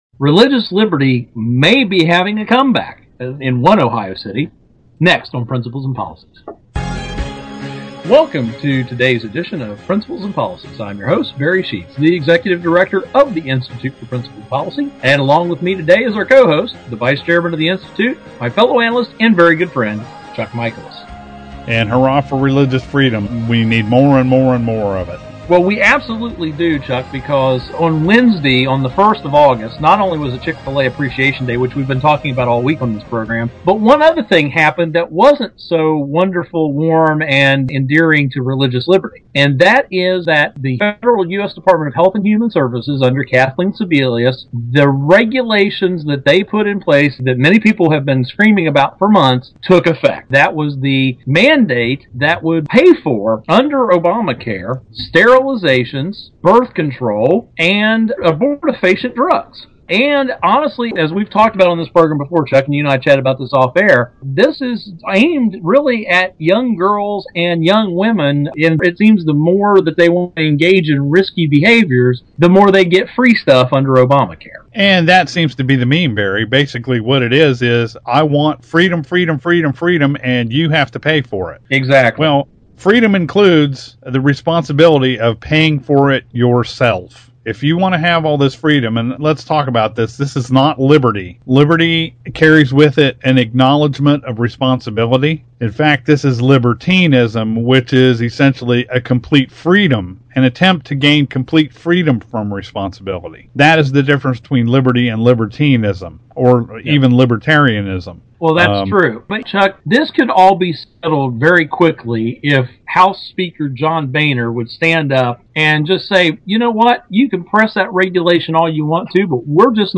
Our Principles and Policies radio show for Friday August 3, 2012.